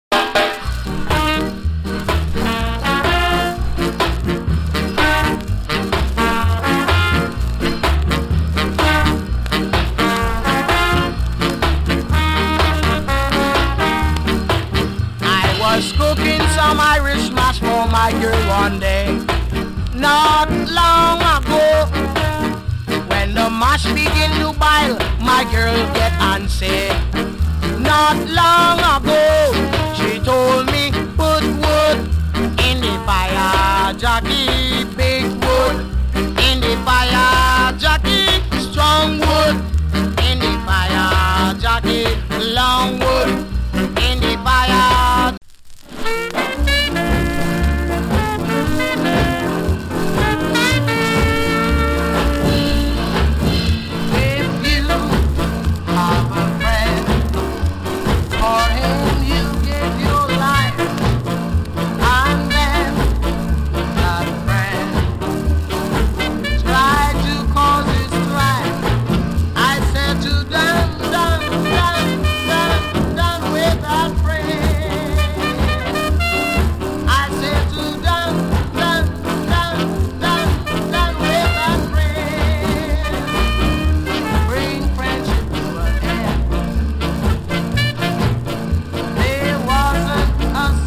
チリ、パチ、ジリノイズ少々有り。
COMMENT 65年 SKA BIG SHOT !! FLIP SIDE も NICE VOCAL SKA !